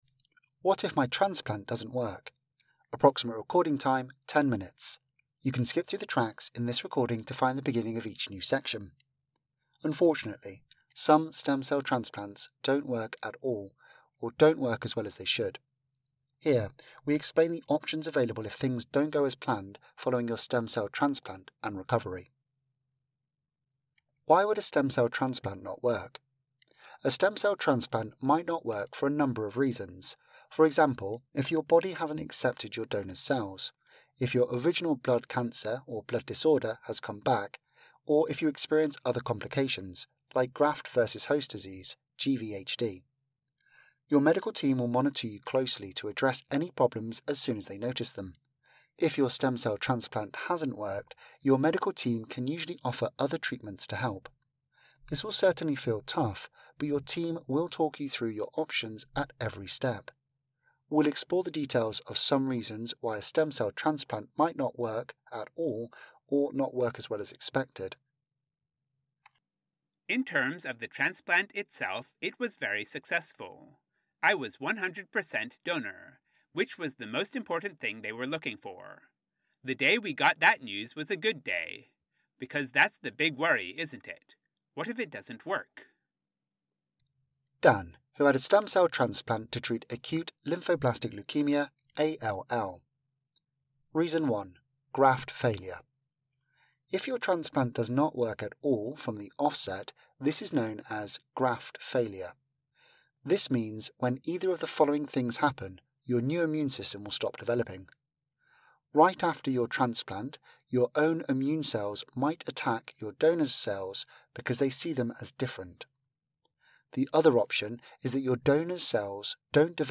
Audio version of Anthony Nolan's patient information: What if my transplant doesn't work?